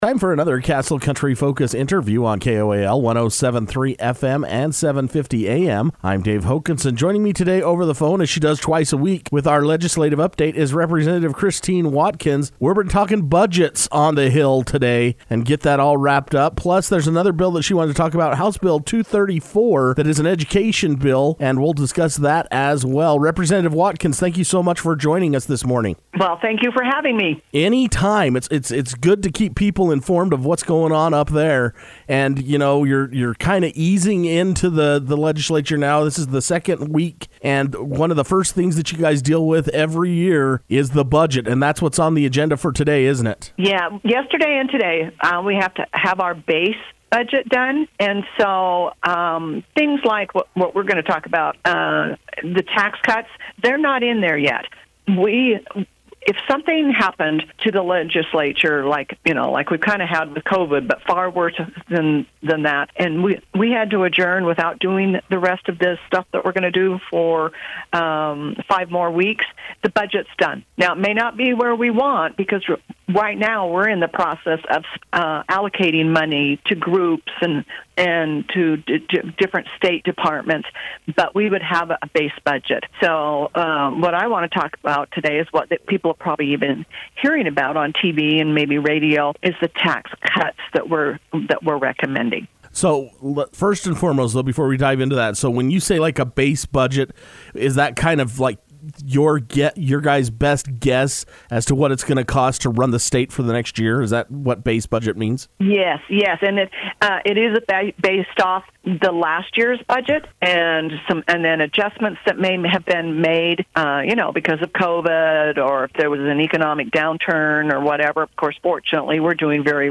Things are moving along at the Utah Legislative Session and once again Castle Country Radio spoke over the telephone with Representative Christine F. Watkins on Thursday morning to get another update this week. She explained that they are discussing budgets, tax cuts, and House Bill 234.